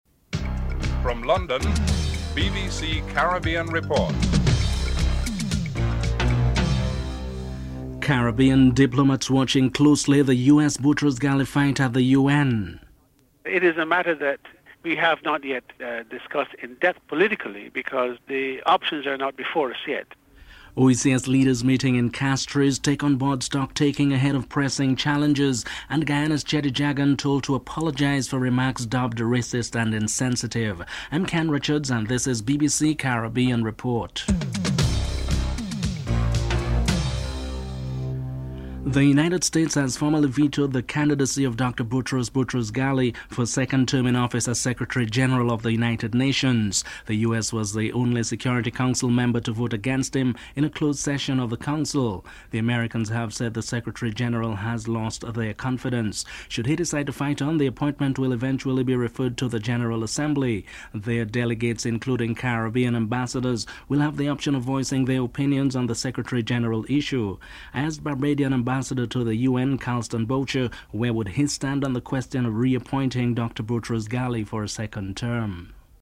1. Headlines (00:00-00:38)
Prime Minister Manuel Esquivel is interviewed (06:56-09:15)